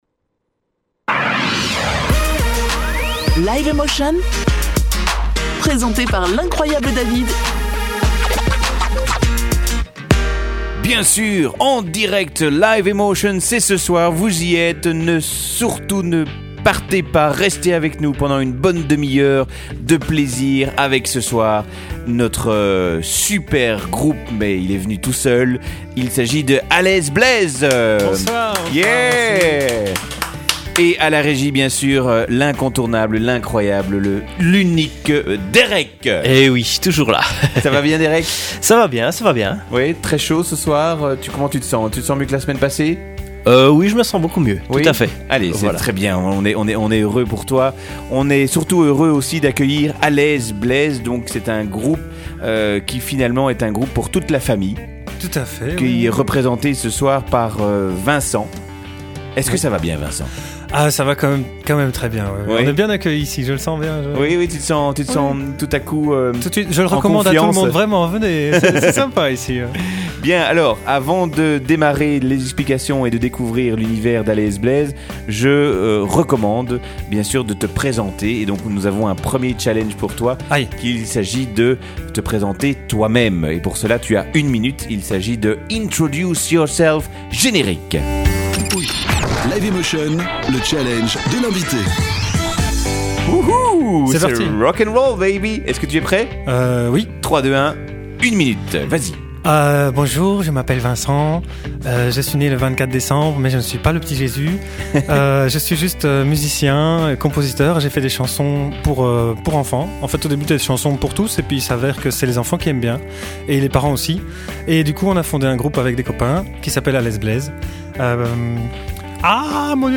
Emission « Live in Motion » – Radio Emotion-Braine-l’Alleud : 34 minutes de rencontre avec A l’aise Blaise avec deux défis !